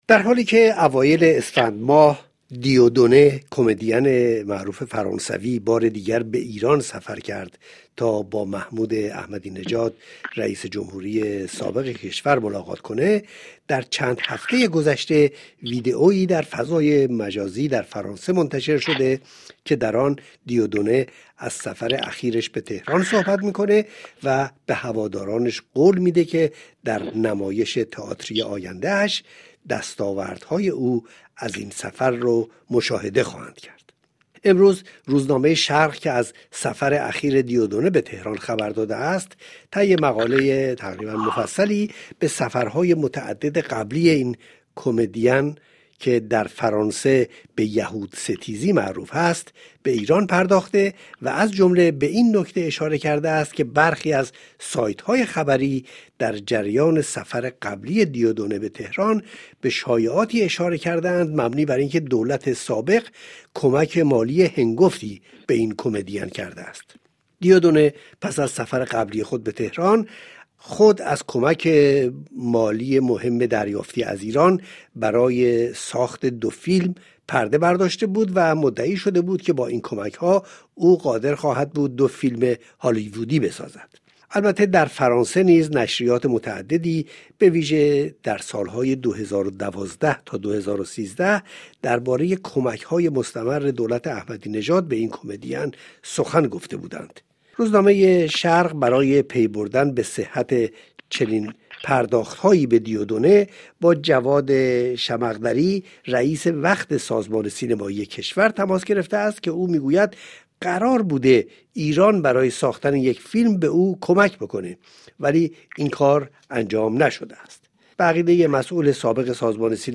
رادیوفرانسه: جواد شمقدری، مسئول سابق سازمان سینمائی، در گفتگو با “شرق”، به علت پرداخت پول به “دیوُ دوُنه”* اشاره می کند و می‌گوید: «به هر حال ساخت اینگونه فیلم‌ها یک حرکت متقابل بود.